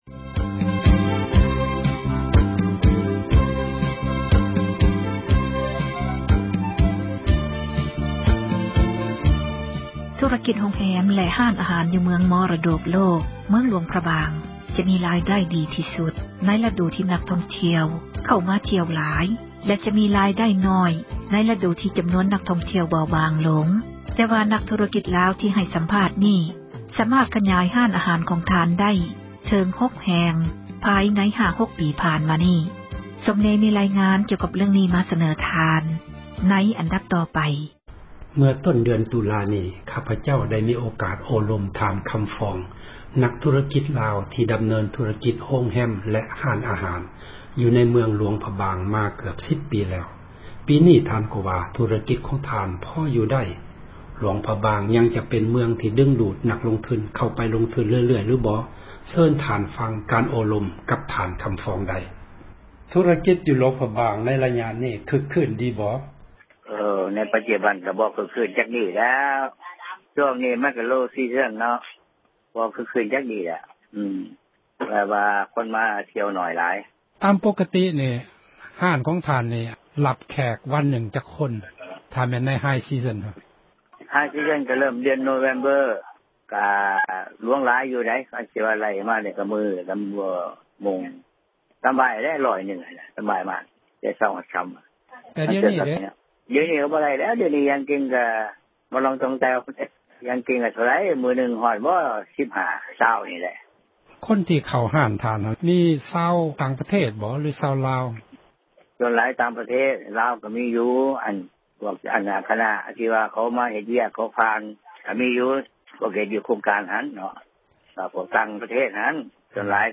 ສັມພາດນັກທຸຣະກິຈ ເມືອງມໍຣະດົກໂລກ